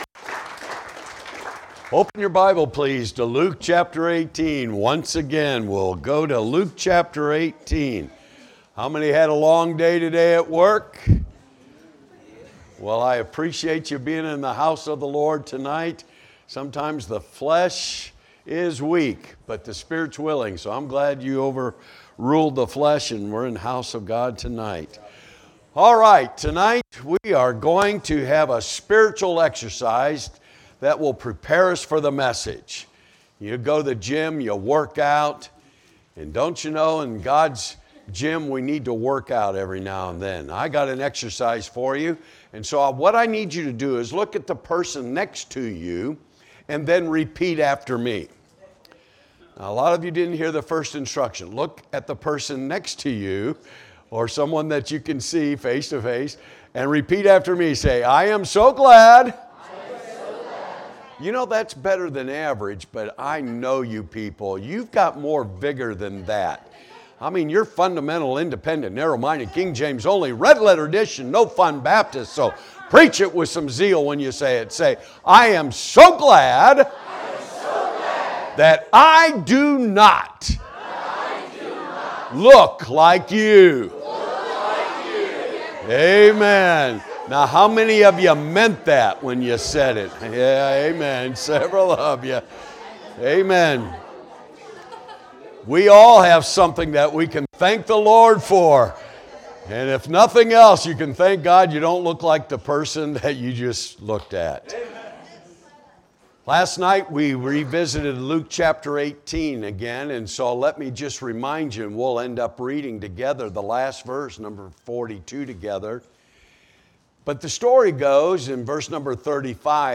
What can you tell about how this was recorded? Tuesday Evening Revival Service – Guest Speaker